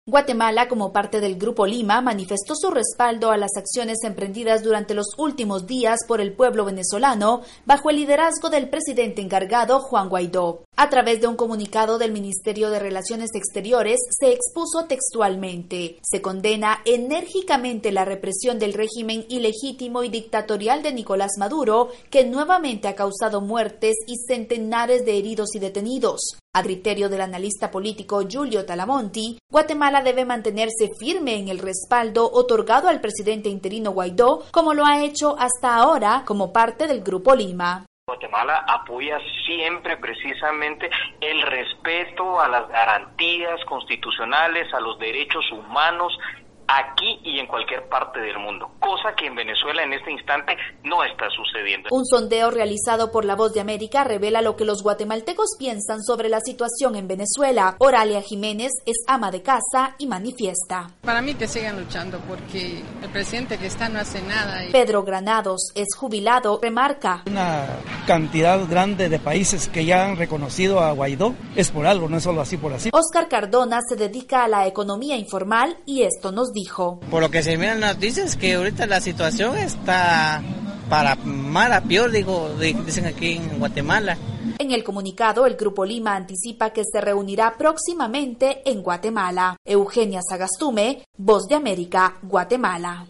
Un sondeo realizado por la Voz de América revela lo que los guatemaltecos piensan sobre la situación en Venezuela.
VOA: Informe desde Guatemala